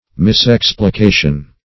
Misexplication \Mis*ex`pli*ca"tion\, n. Wrong explication.